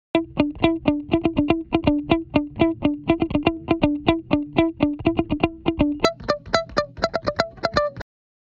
▶ギターループにバリエーションを加えた結果